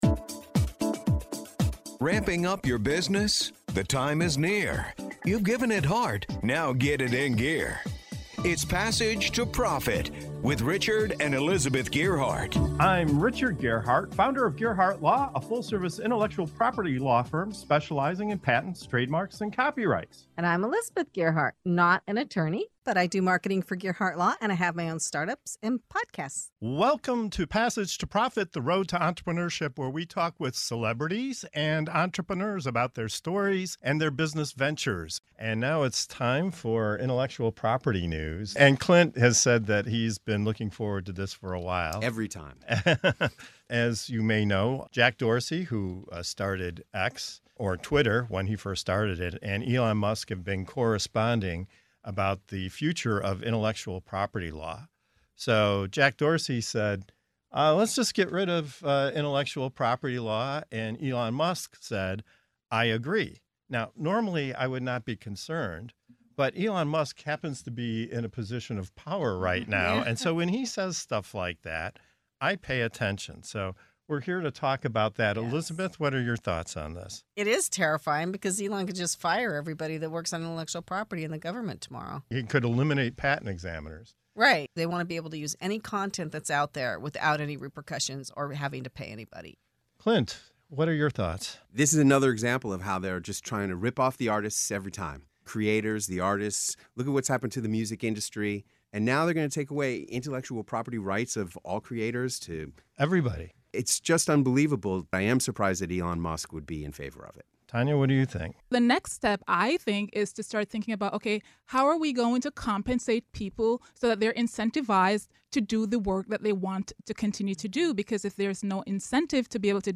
What happens when tech titans like Jack Dorsey and Elon Musk say it's time to ditch intellectual property laws? In this eye-opening segment of "Intellectual Property News" on Passage to Profit Show, the team dives into the explosive idea of a world without IP—what it means for creators, innovation, and the future of ownership.